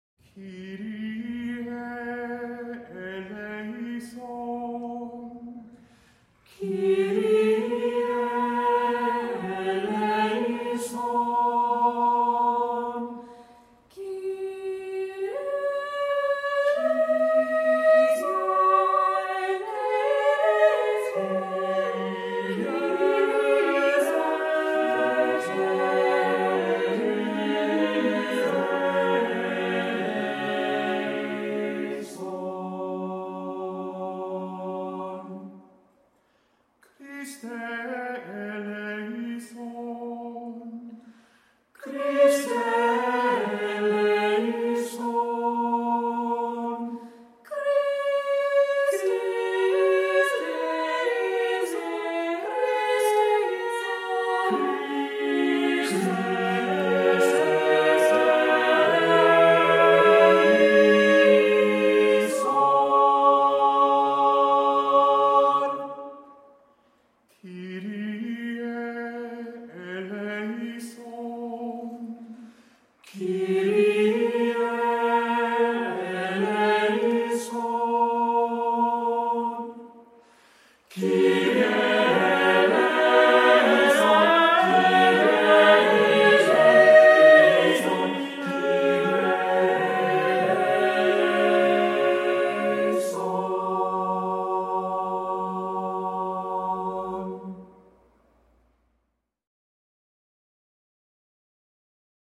Voicing: a cappella,Assembly,SATB,Cantor